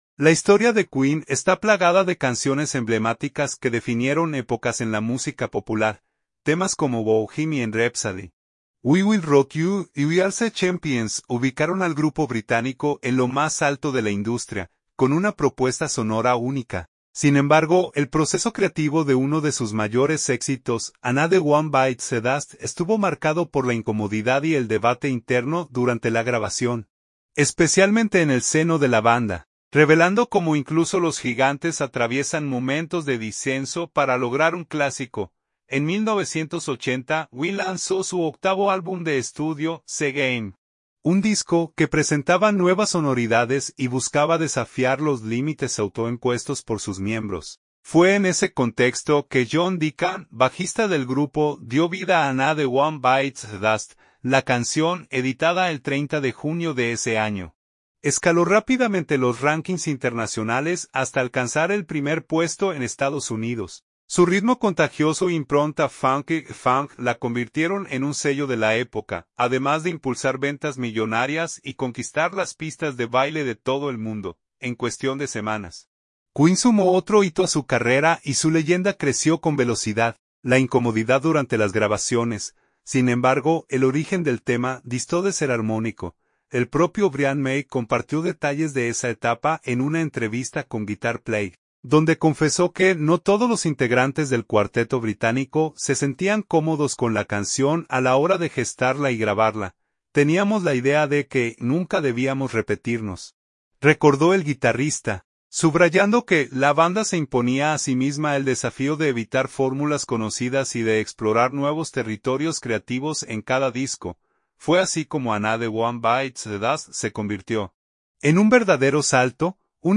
apostó por un sonido minimalista y compacto.